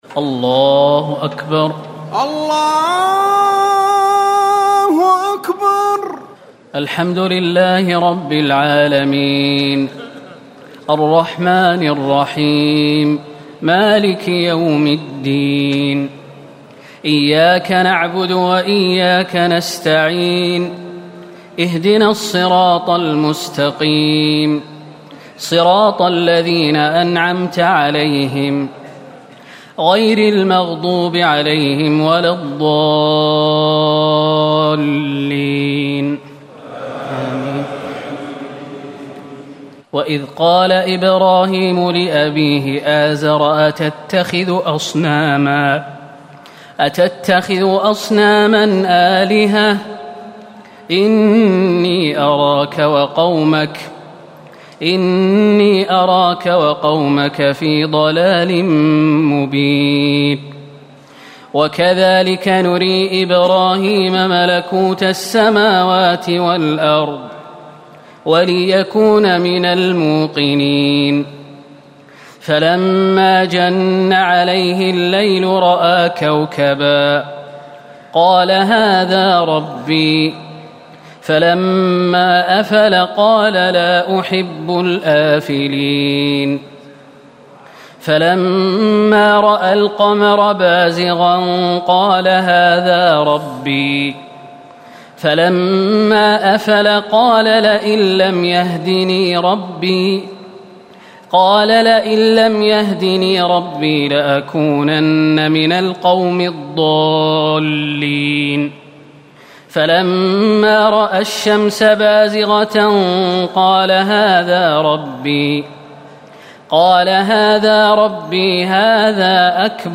تراويح الليلة السابعة رمضان 1439هـ من سورة الأنعام (74-150) Taraweeh 7 st night Ramadan 1439H from Surah Al-An’aam > تراويح الحرم النبوي عام 1439 🕌 > التراويح - تلاوات الحرمين